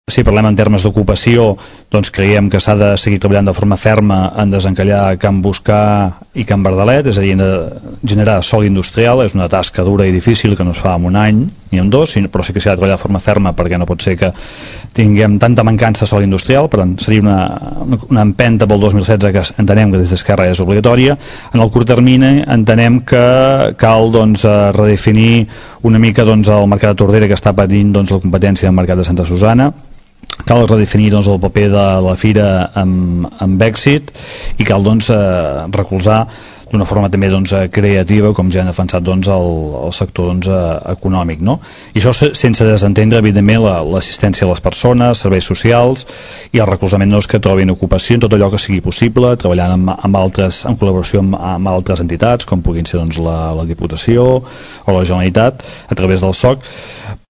La tertúlia de polítics se celebra cada mes, la següent setmana després del plenari municipal.